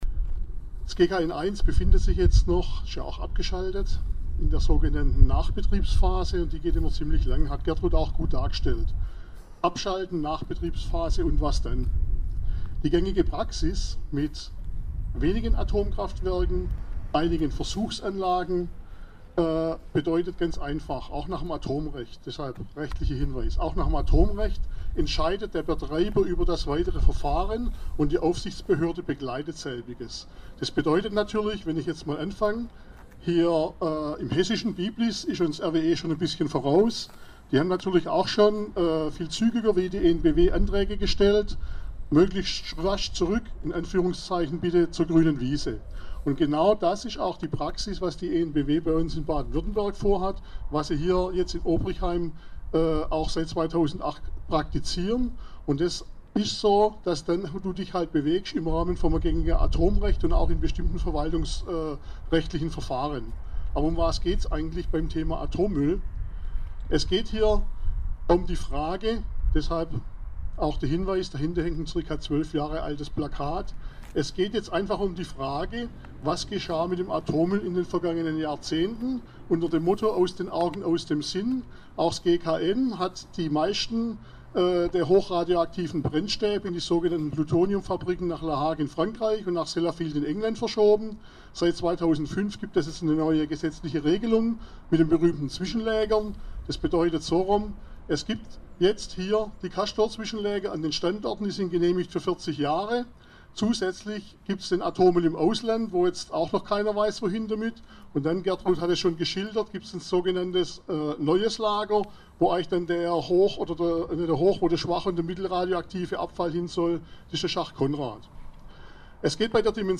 Rückblick zum Sonntagsspaziergang am 7. April 13 In einem ausführlichen Beitrag informierte bei diesem Sonntagsspaziergang die Initiative "Atomerbe Obrigheim" über die Risiken, Gefahren und Mängel beim AKW-"Rückbau" in Obrigheim. Die fehlende Öffentlichkeitsbeteiligung, mangelhafte Umweltverträglichkeitsprüfungen, die Praxis des "Freimessens" und der Umgang mit all dem Atommüll waren auch Gegenstand des zweiten Redebeitrags.